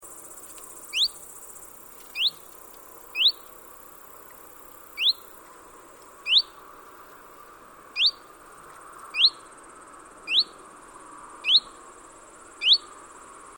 Chiffchaff calls
All from Northwestern Estonia, 10-11 September 2005.